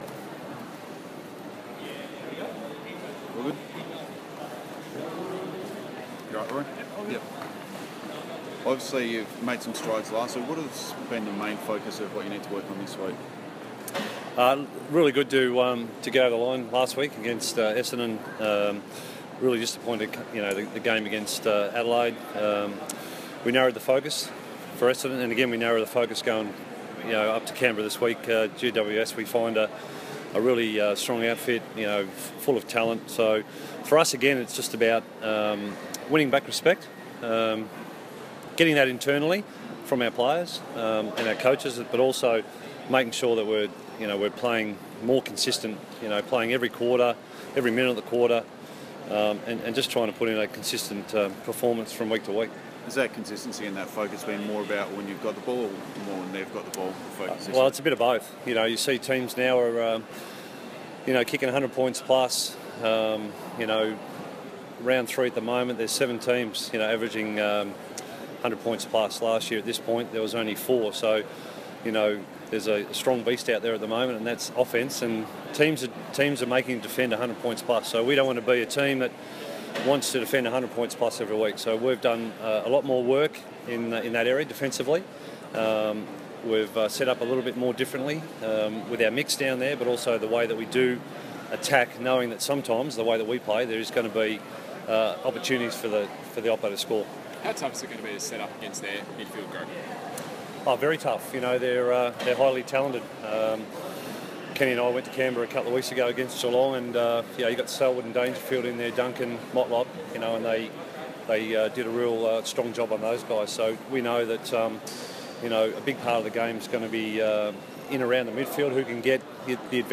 Garry Hocking Press Conference - Saturday 16 April
Garry Hocking talks to the media before Port Adelaide flies out to play GWS in Canberra.